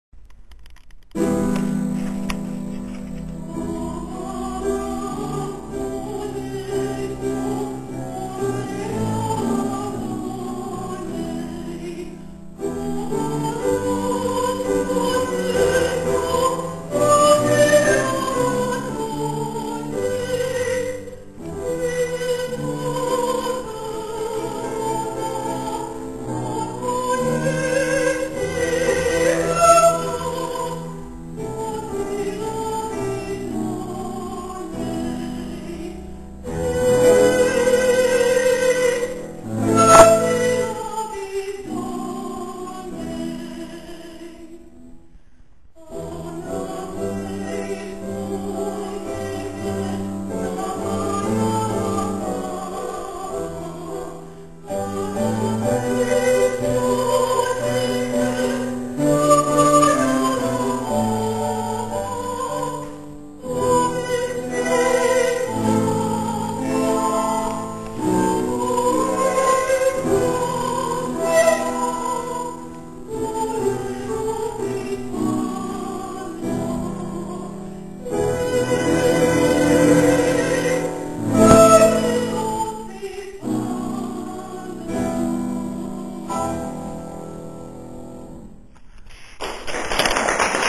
20. komorní koncert na radnici v Modřicích
- ukázkové amatérské nahrávky, v ročence CD Modřice 2006 doplněno:
mezzosoprán
klavír